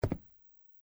在较硬的路面上行走脚步单生－左声道－YS070525.mp3
通用动作/01人物/01移动状态/01硬地面/在较硬的路面上行走脚步单生－左声道－YS070525.mp3